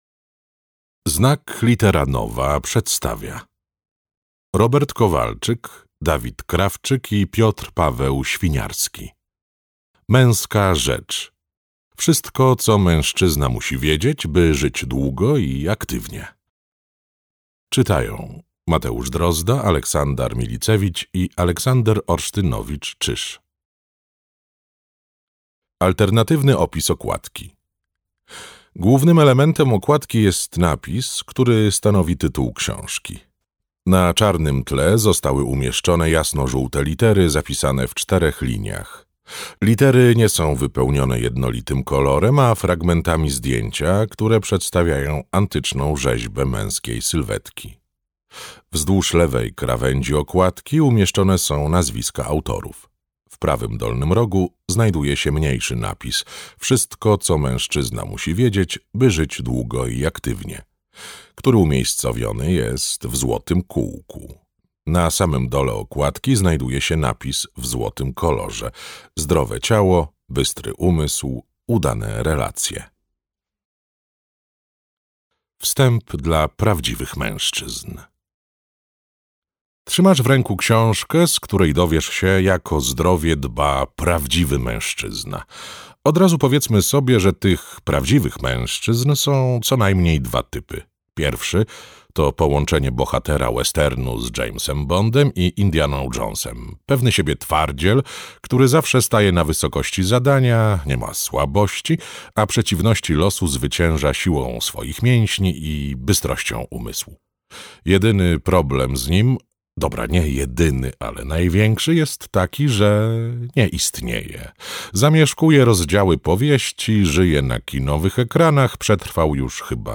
Audiobook Męska rzecz.